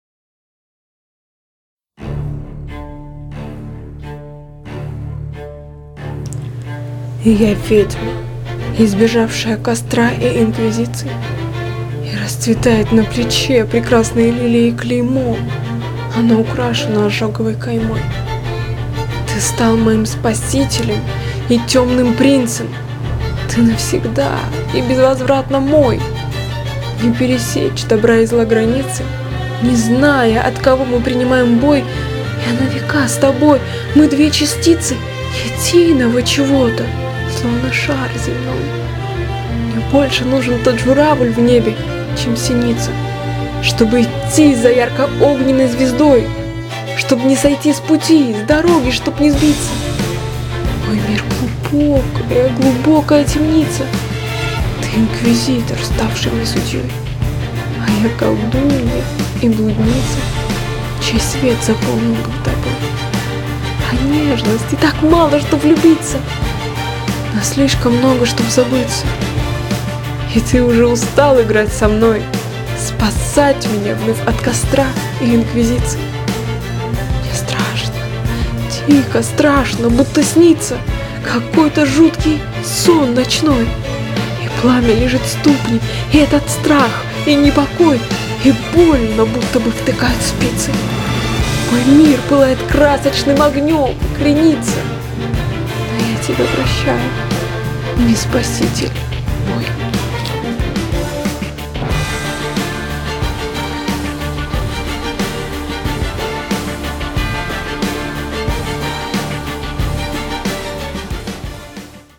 люблю очень твой голос, твои интонации и вообще: твою эту способность - воздействовать на слушателей!! я полностью окунаюсь в твои мысли, сохраняя для себя маленькую возможность: применять это всё для себя... ради своего внутреннего мира...